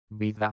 Primo tipo
Si pronunciano chiudendo le labbra.